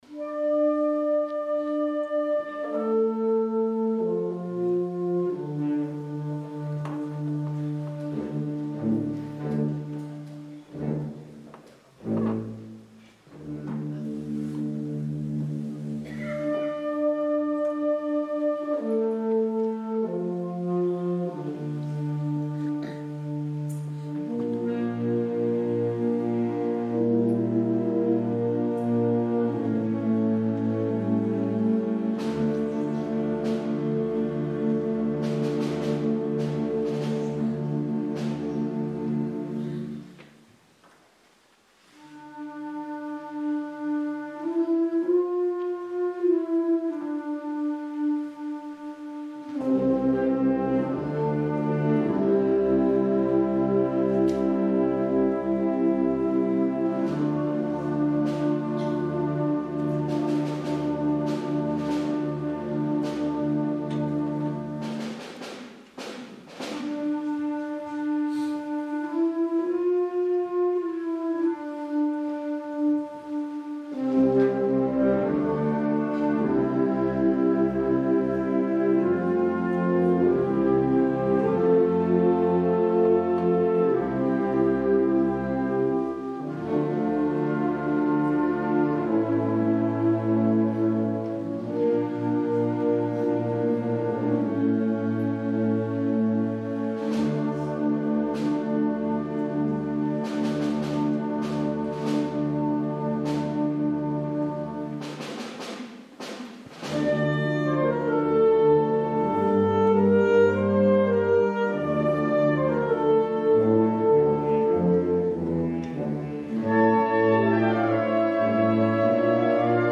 Concert de Setmana Santa. Auditori de Porreres.